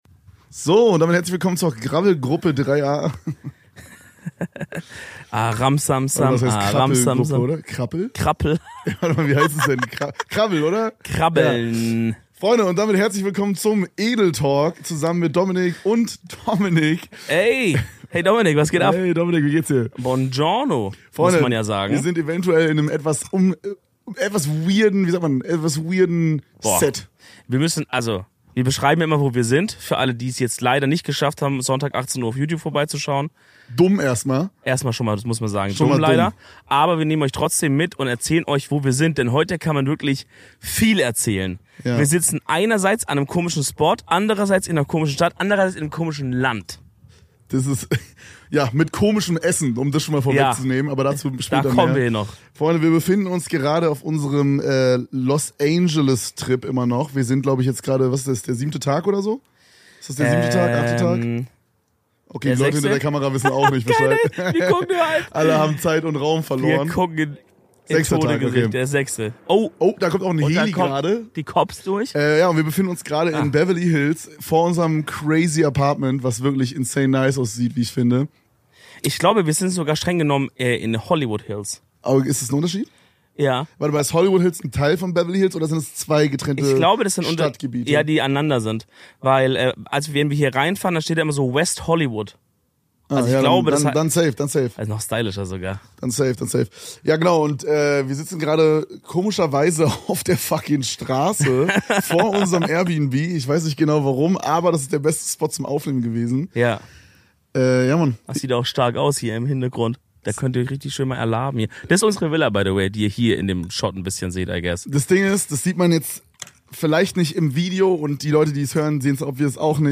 Wir sitzen auf der Straße vor unserem Airbnb in den Hollywood Hills und reden wieder mal übers kacken.